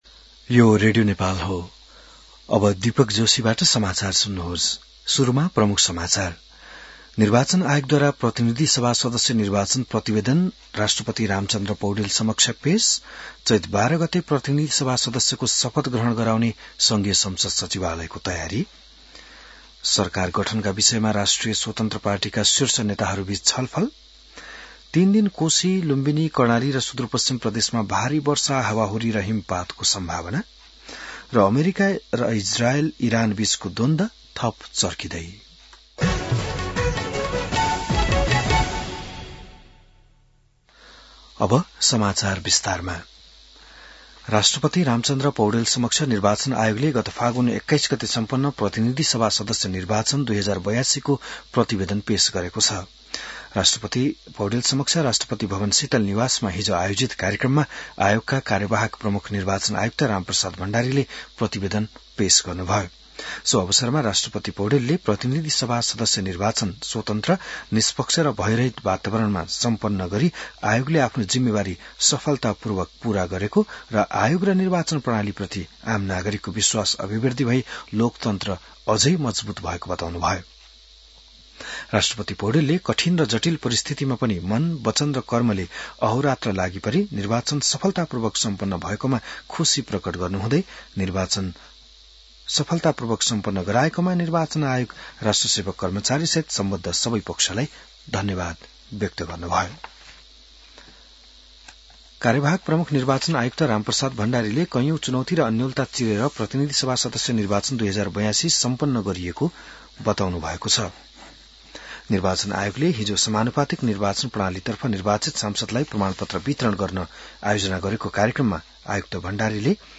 An online outlet of Nepal's national radio broadcaster
बिहान ९ बजेको नेपाली समाचार : ६ चैत , २०८२